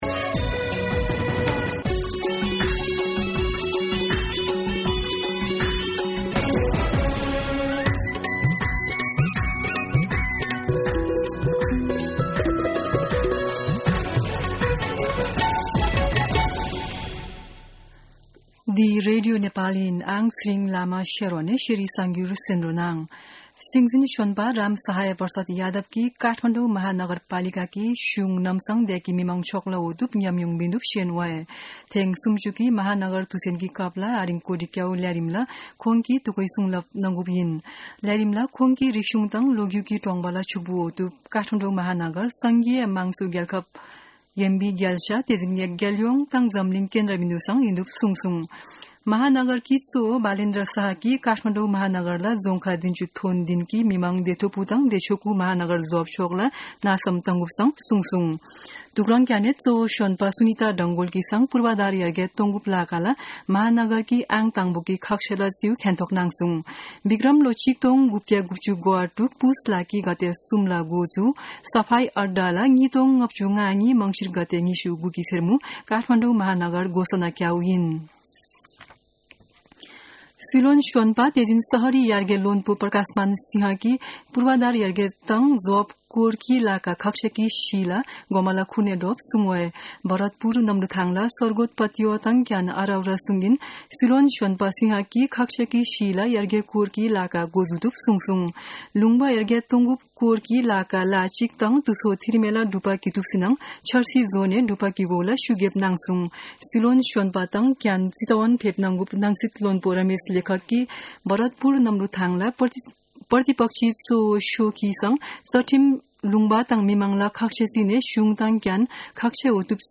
शेर्पा भाषाको समाचार : ३० मंसिर , २०८१
Sherpa-News-.mp3